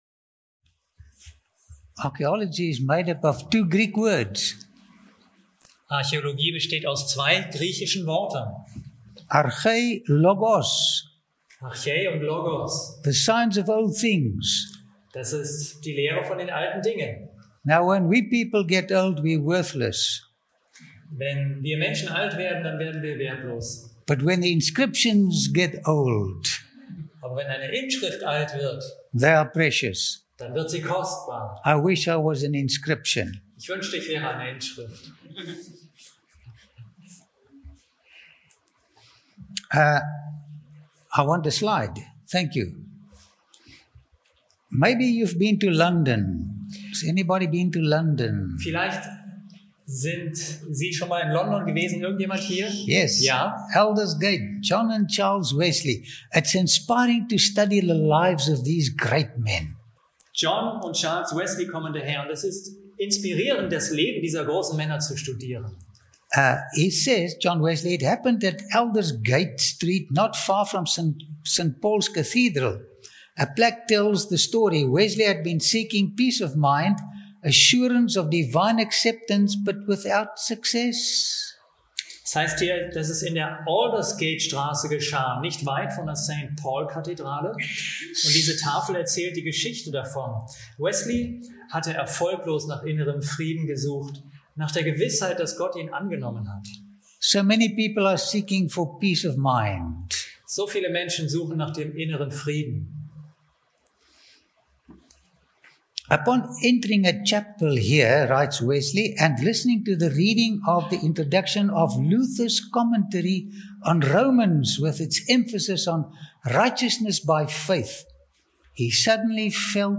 In den reich bebilderten Vorträgen erhält der Zuschauer nicht nur faszinierende Einblicke in vergangene Kulturen, sondern begegnet Menschen, die vor Tausenden von Jahren ganz ähnliche Freuden und Sorgen hatten wie wir – und deren ermutigende Erfahrungen auch heute noch erlebbar sind, wenn wir von ihnen lernen und den verlorenen Schatz des Vertrauens in Gott wiederfinden.